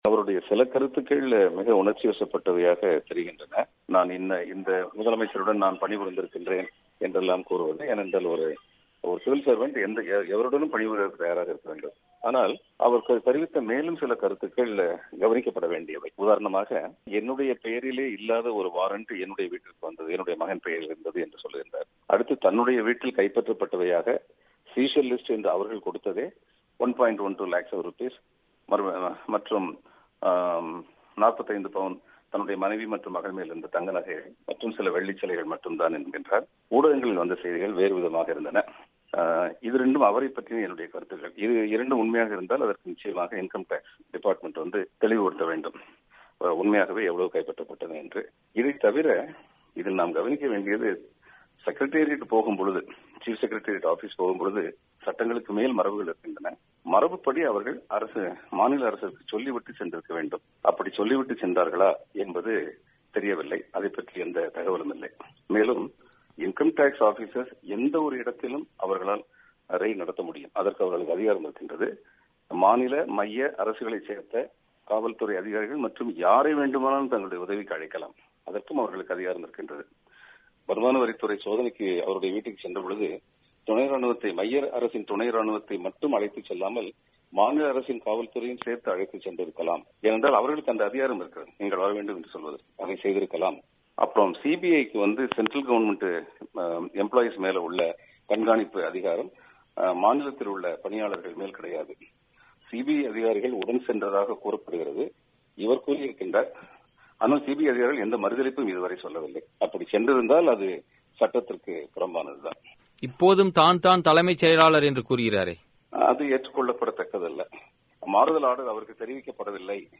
தனது வீட்டில் வருமான வரித்துறை சோதனை நடத்தியது குறித்து தமிழகமுன்னாள் தலைமைச் செயலர் ராம மோகன ராவ் தெரிவித்த குற்றச்சாட்டுக்கள் நியாயமானவையா என்பது குறித்து மேற்கு வங்க மாநில முன்னாள் கூடுதல் தலைமைச் செயலர் பாலச்சந்திரன் பிபிசி-க்கு அளித்த பேட்டி